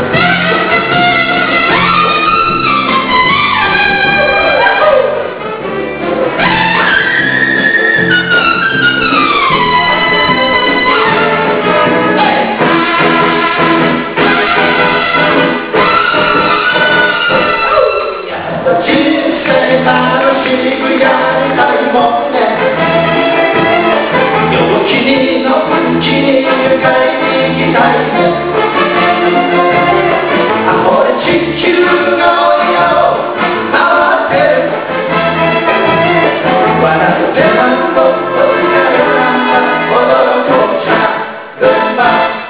丸亀ユニオンジャズオーケストラの荒井注さん追悼コンサート「銭はなくても全員集合」は、２０００年６月１０日、約１８０人のお客様が来場され無事終了いたしました。
この日ハイトーンが冴え渡りがんがん聴かせてくれた。